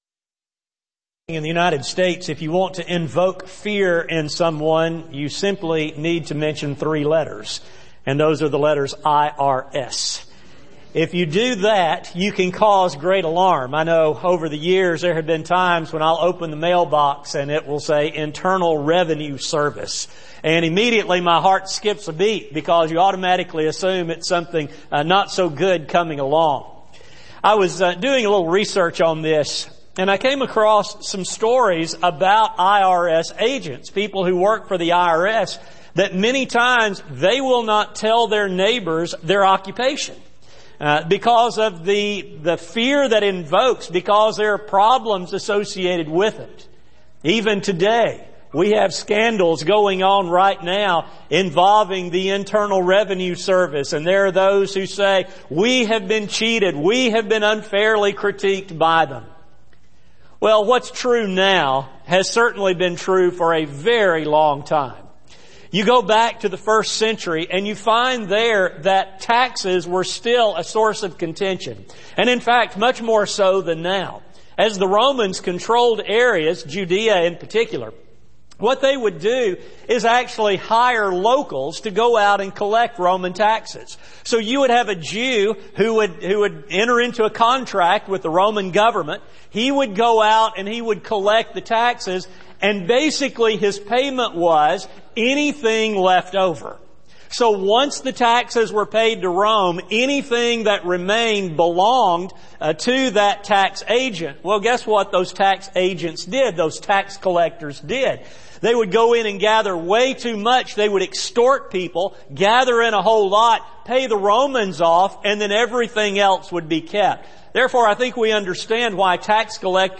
Service: Sun AM Type: Sermon